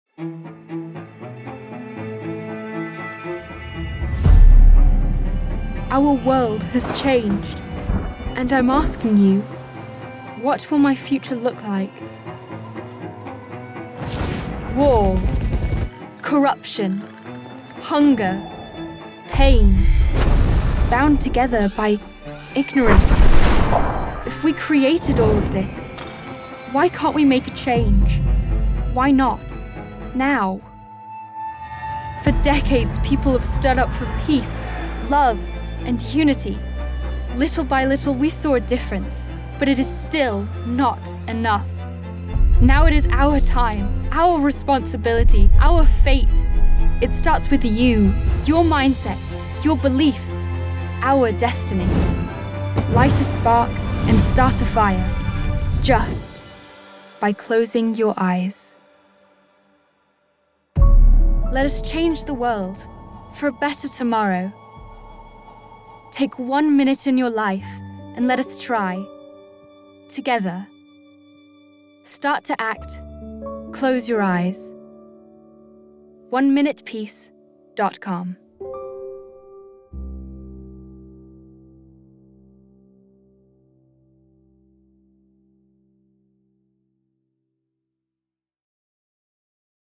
British Voice Actress Age 16-24
British RP, London
Young Adult